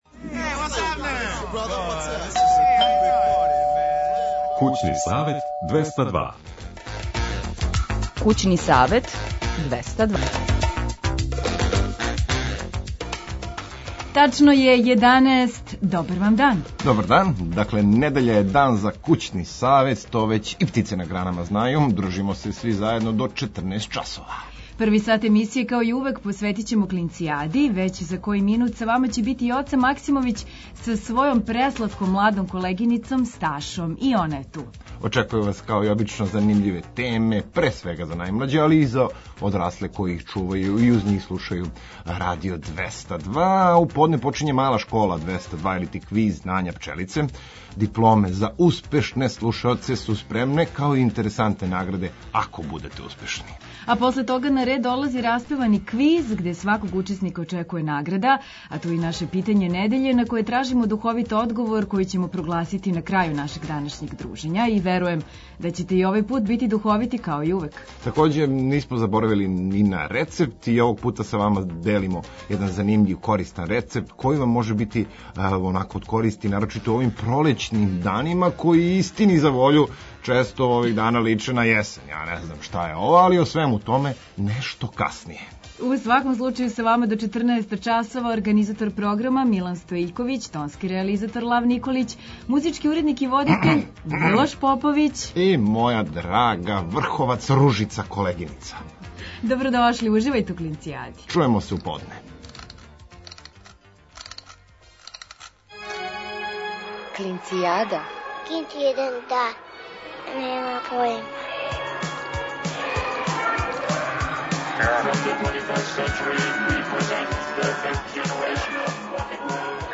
Од подне почињемо дружење у квизу знања „Пчелице”, а од 13 часова, уз гитару у студију, започињемо нови „Распевани квиз”. Као и обично, недељом у време ручка, дајемо идеју за спремање занимљиве и једноставне хране.